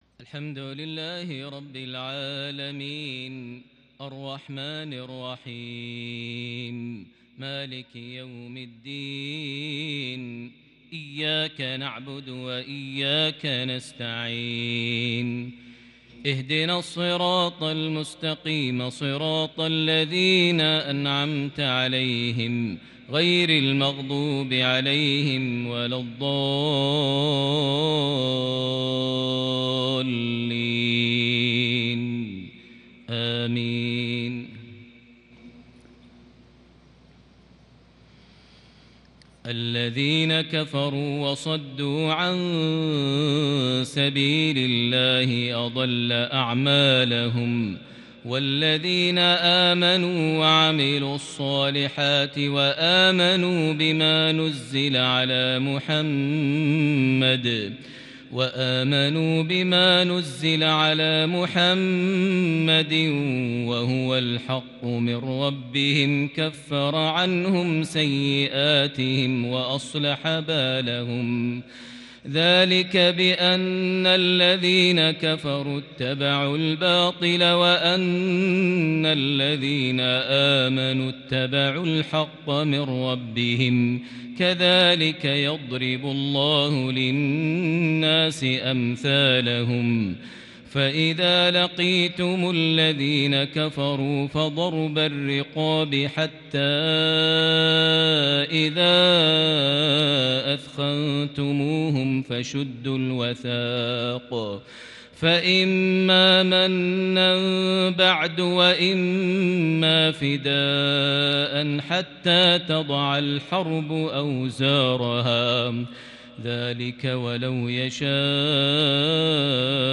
فجرية مميزة للغاية بالكرد البديع من سورة محمد ( 1-19) | الأربعاء 22 ذو الحجة 1441هـ > 1441 هـ > الفروض - تلاوات ماهر المعيقلي